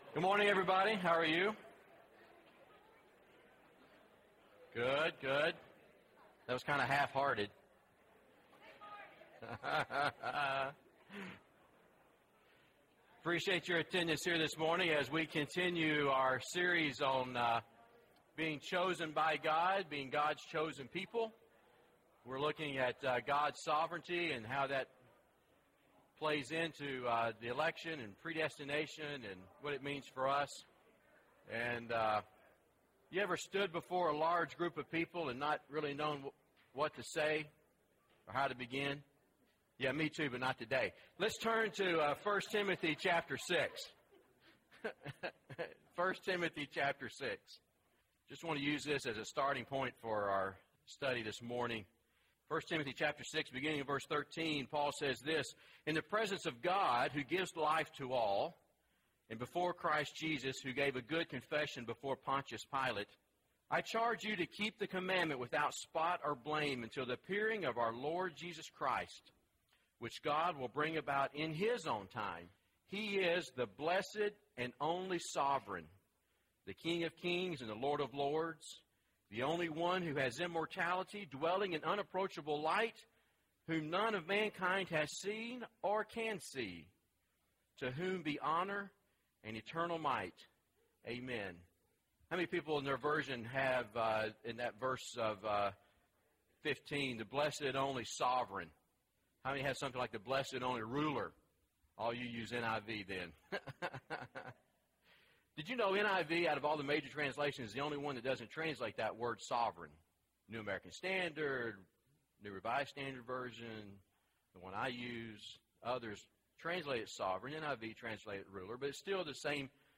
God’s Sovereignty and Man’s Free Will (4 of 13) – Bible Lesson Recording